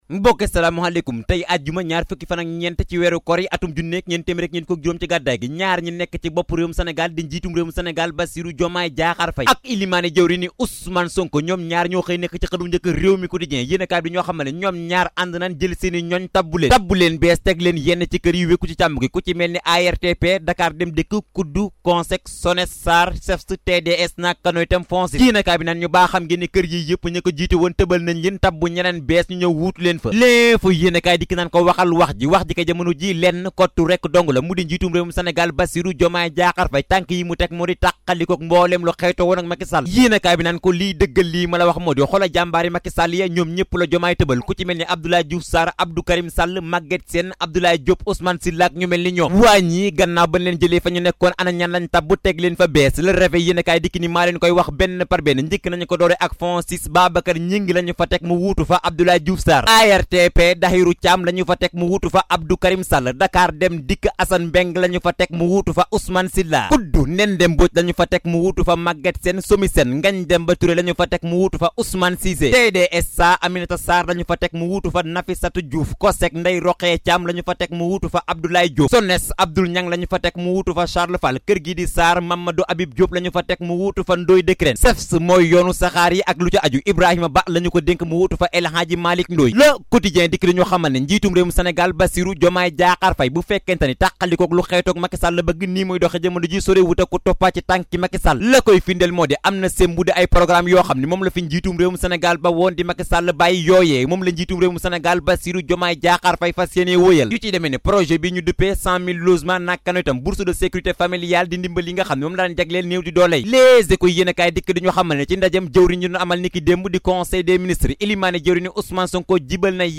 Revue de presse de Leral de ce vendredi 3 mai 2024